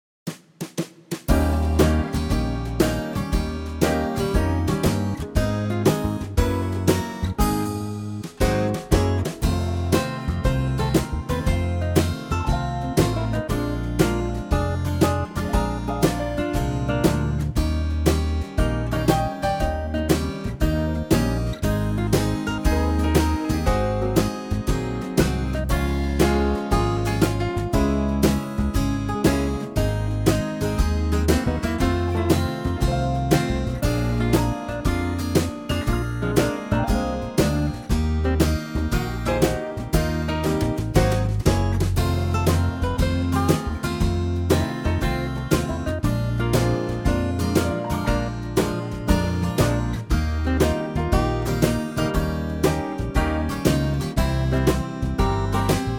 Unique Backing Tracks
LAPSTEEL GUITAR & SOLO REMOVED!
key - Ab - vocal range - Gb to G